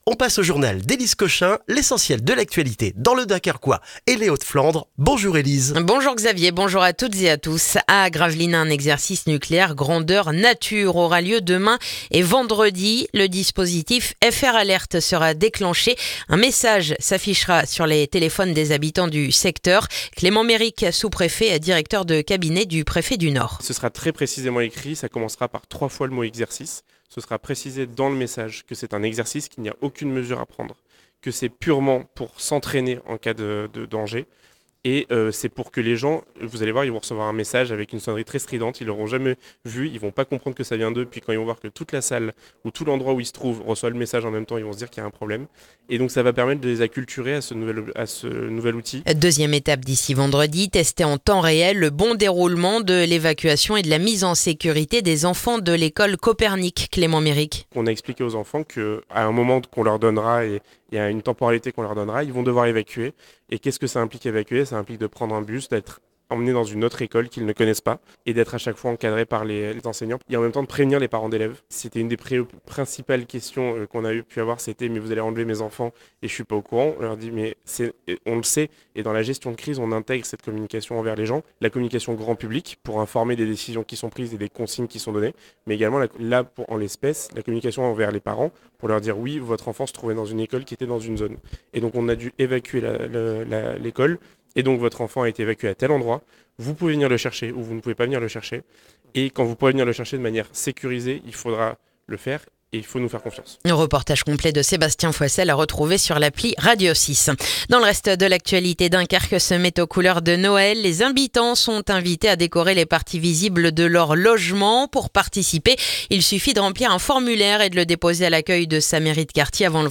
Le journal du mercredi 26 novembre dans le dunkerquois